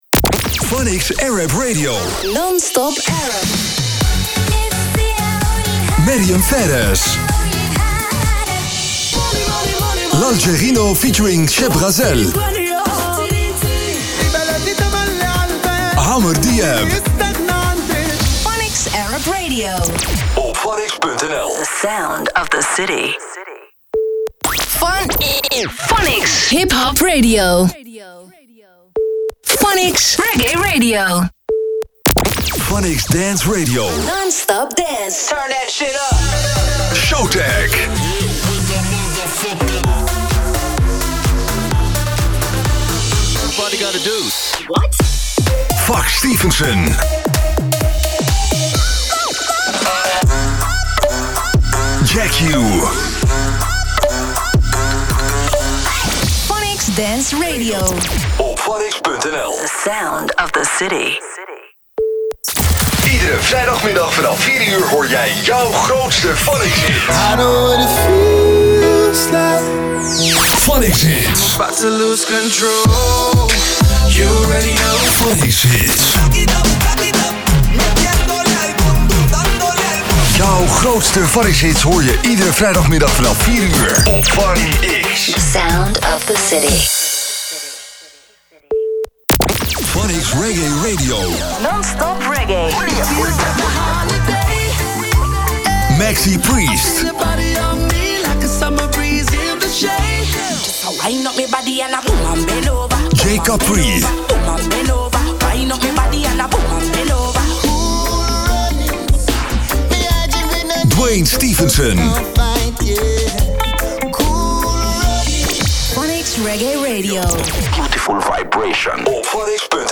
Promo’s en sweepers FunX